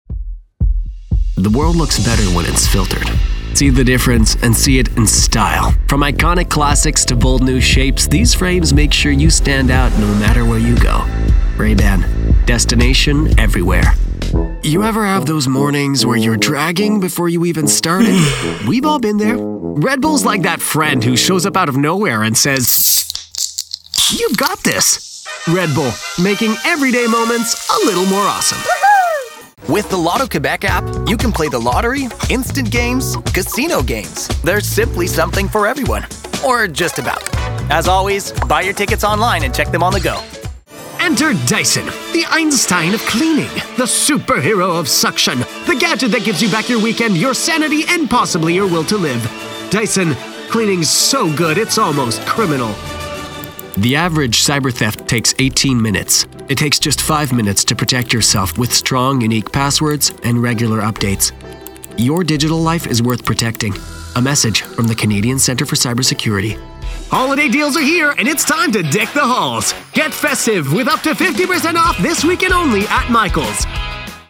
Publicités - ANG